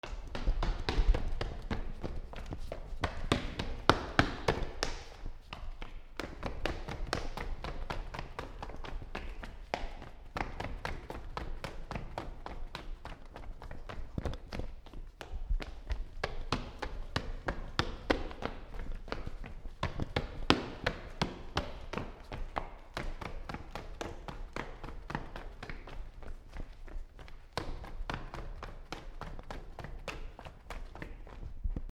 学校階段 スニーカー 走る
/ I｜フォーリー(足音) / I-145 ｜足音 学校
MKH416